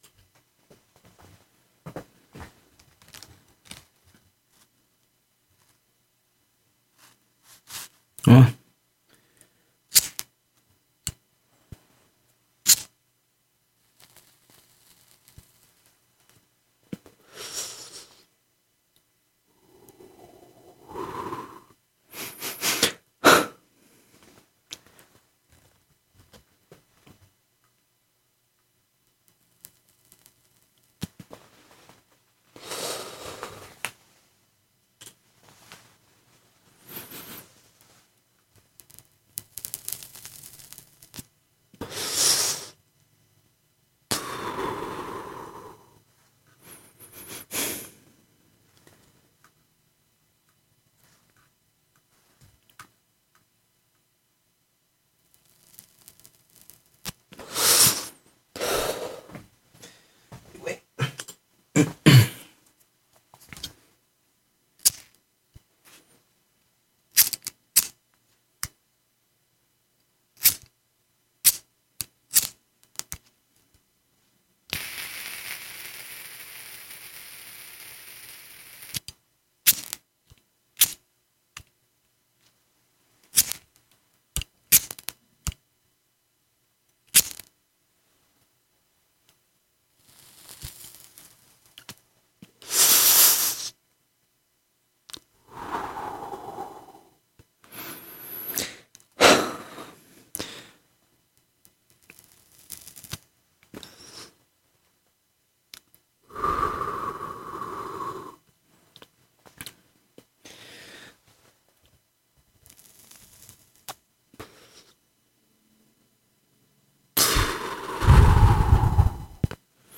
描述：玩转zippo打火机盒
标签： 芝宝 打火机 声音效果
声道立体声